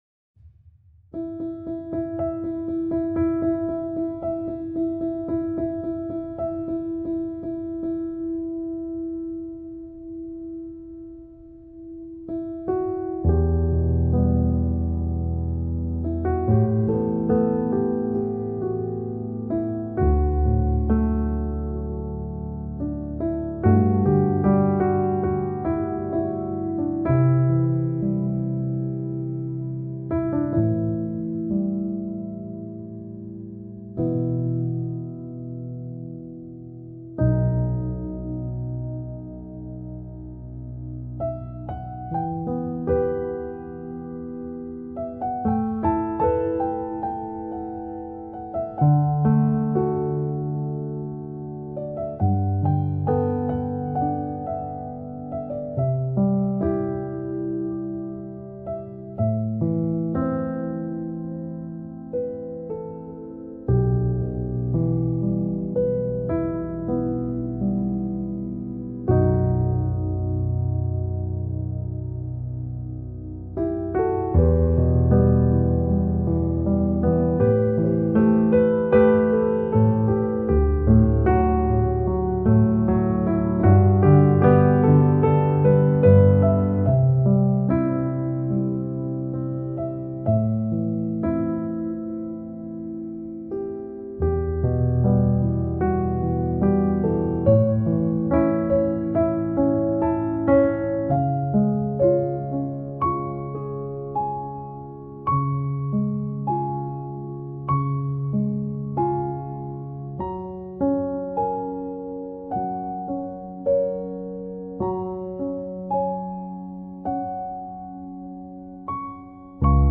سبک آرامش بخش , پیانو , عصر جدید , موسیقی بی کلام
پیانو آرامبخش موسیقی بی کلام نیو ایج